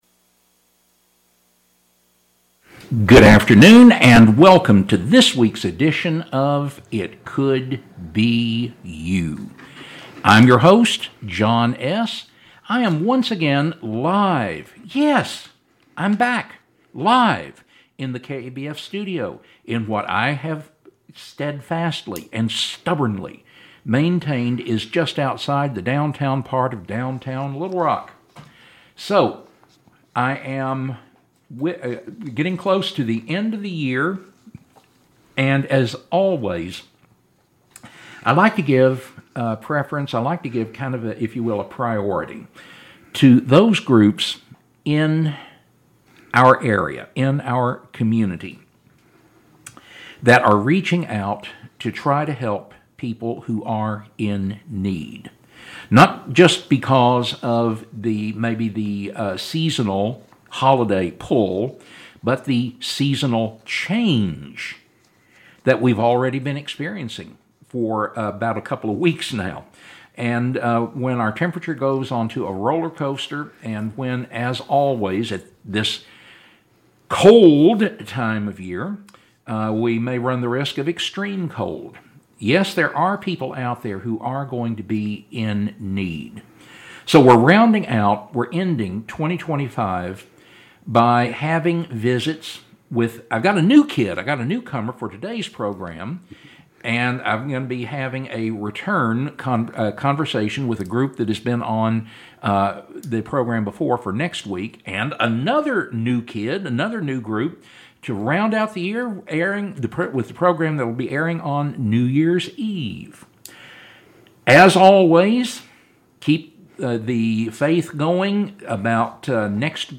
KABF 88.3 FM Interview